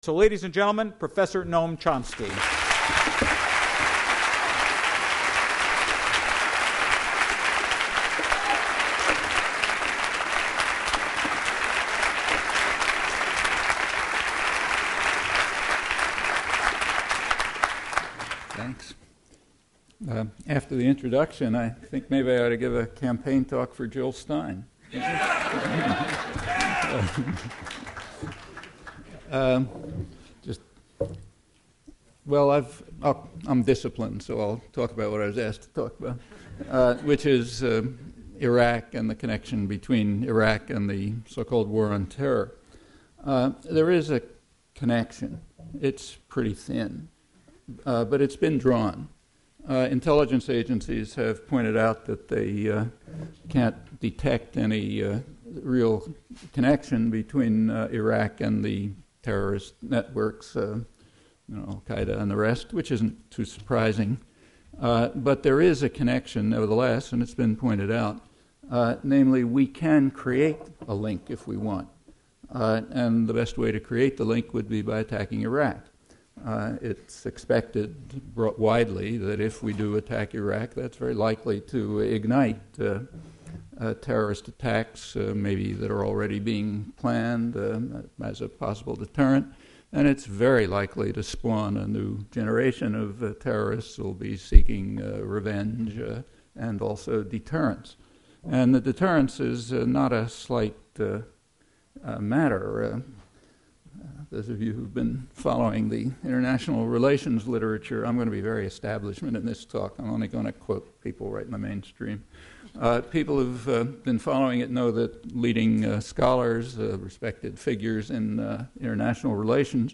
AUDIO: Noam Chomsky 11-5 Talk
chomsky_nov_4_02_talk.mp3